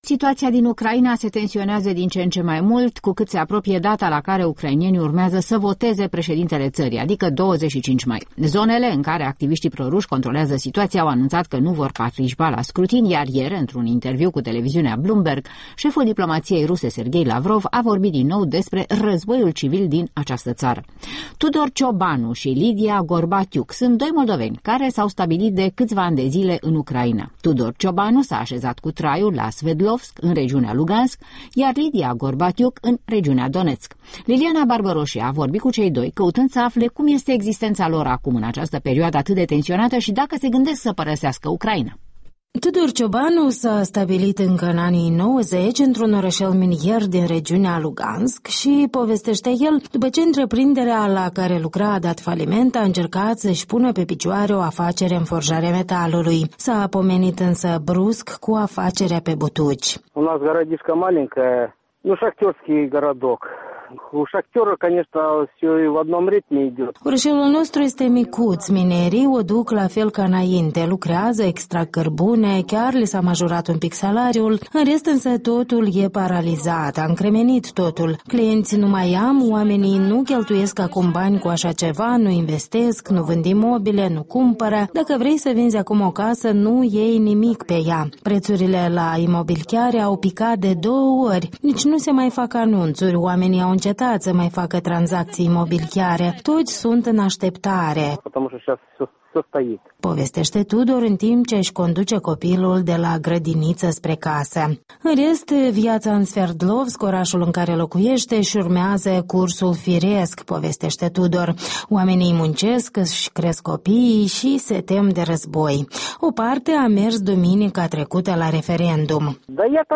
Prin telefon: opinii ale unor moldoveni stabiliți în Ucraina de răsărit despre criza actuală